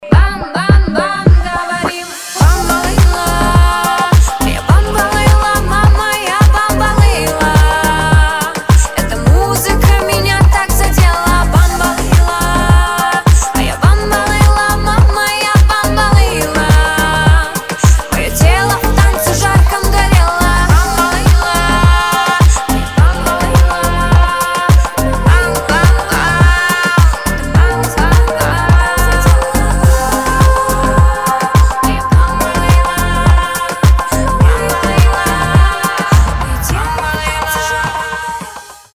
поп
женский вокал
dance
летние